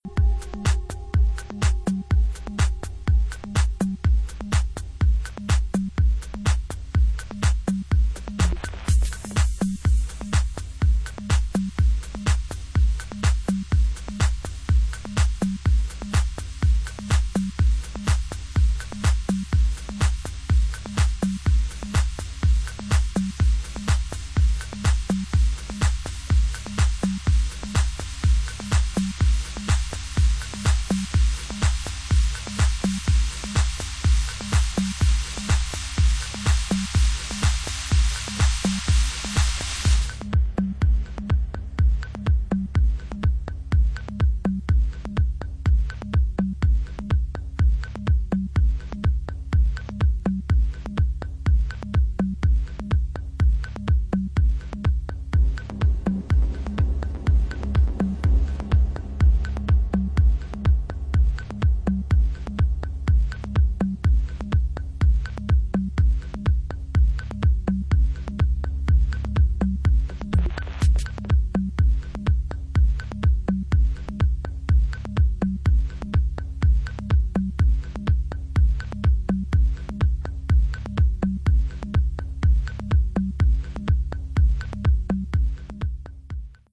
Dub Remix